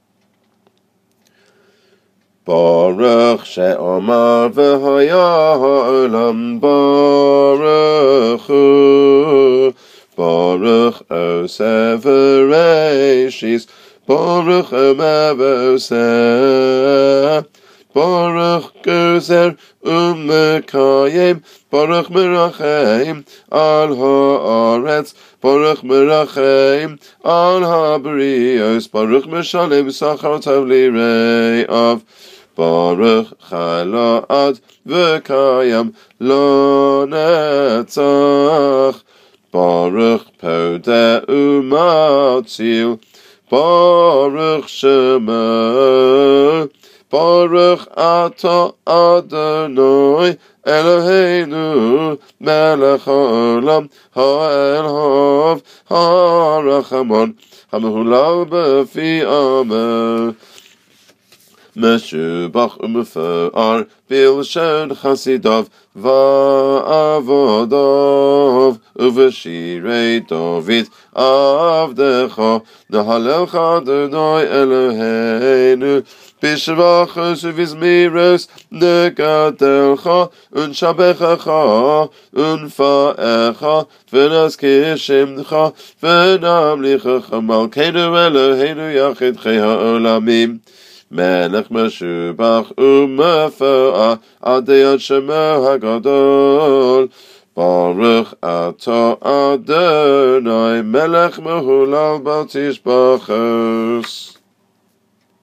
Davening Audios for School (Ashk. Pronunc.)